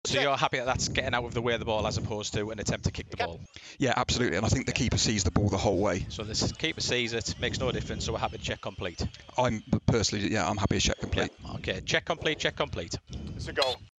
Match official Mic'd up